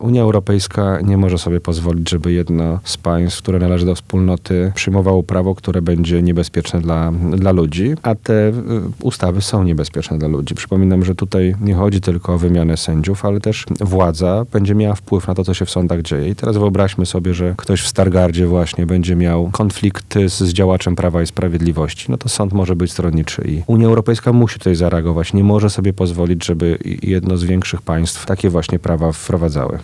Rozmówca Twojego Radia jest zdania, że jeśli nie dojdzie do przełomu w relacjach Polski z Unią Europejską, perspektywa sankcji- zarówno politycznych jak i finansowych jest realna.